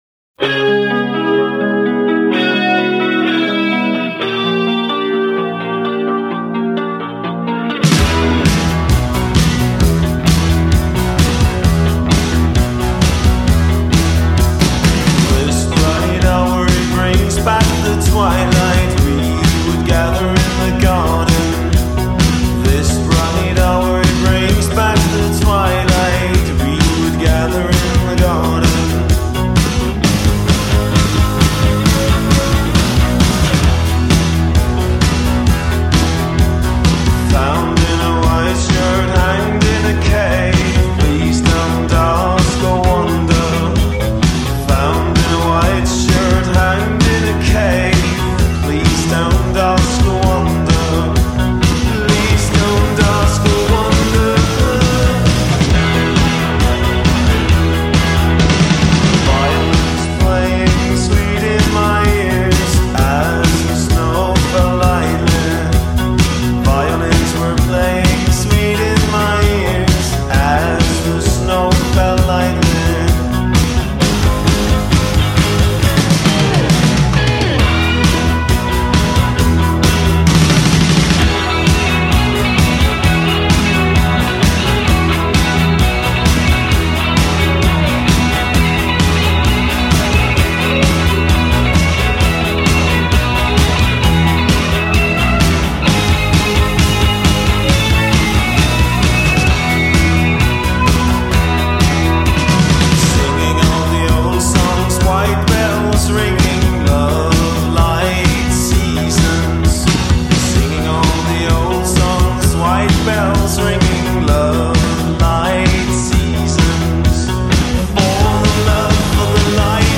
early Danish postpunk act